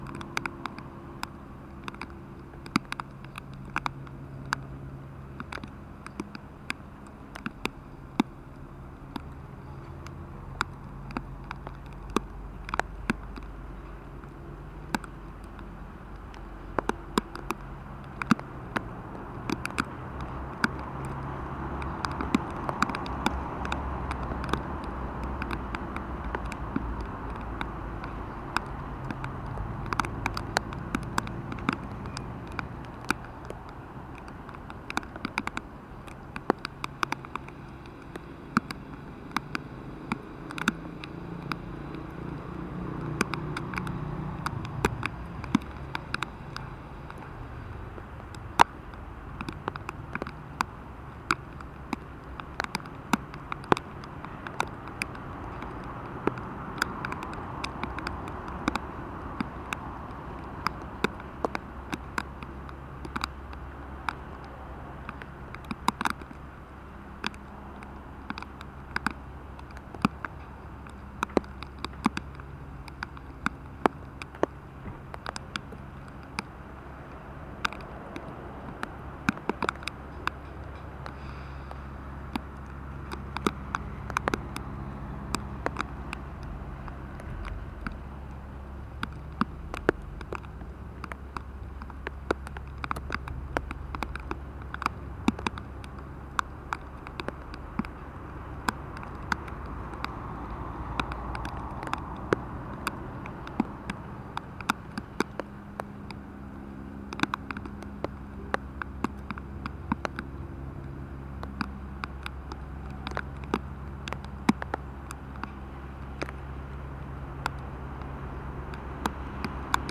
Marcha 8M 9M
Ciudad de Rosario 09 de Marzo 2020